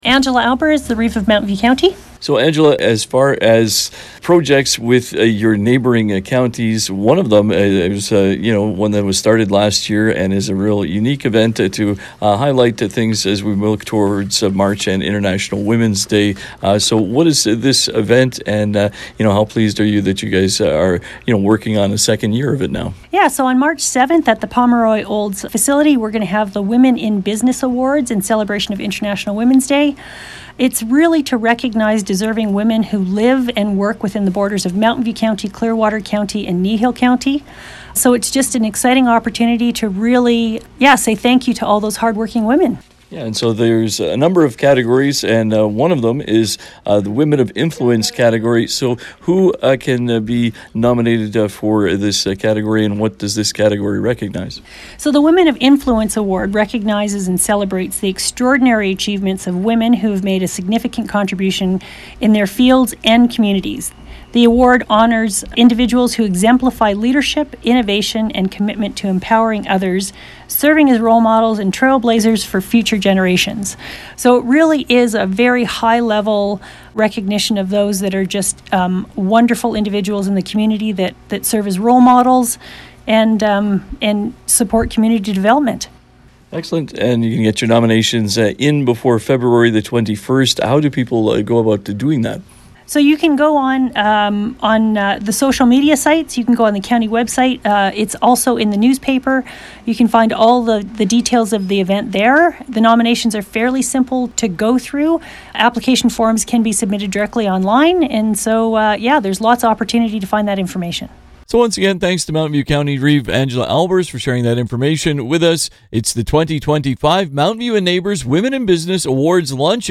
Listen to 96.5 The Ranch’s Community Hotline conversation with Angela Aalbers.